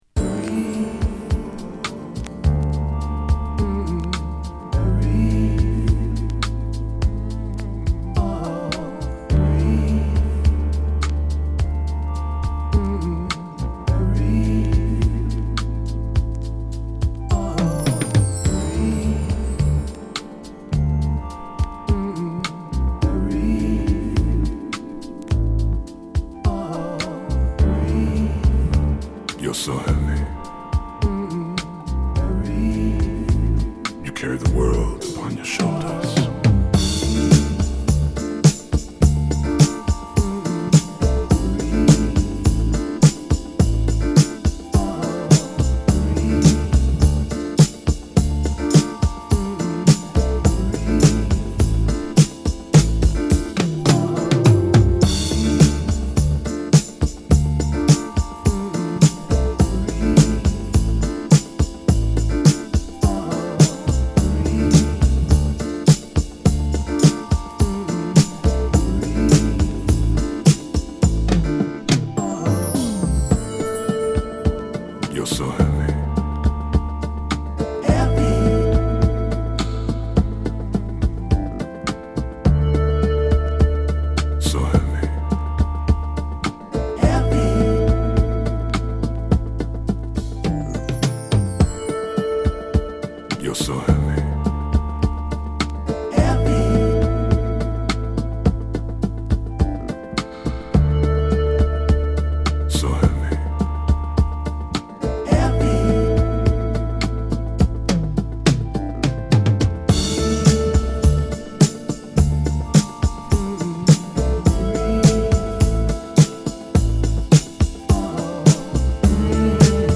Beatdown , Deep House
Mellow Groove